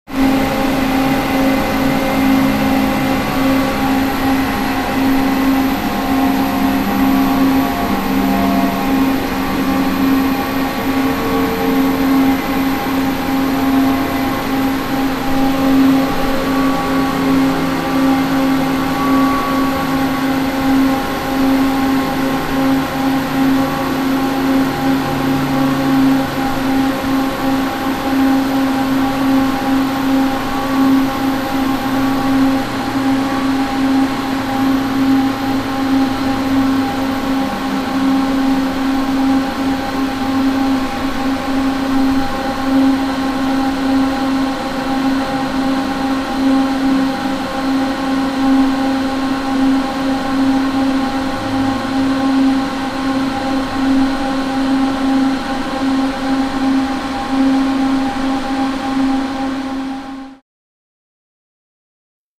Engine; Wave Tunnel Motor Run Steady, Hum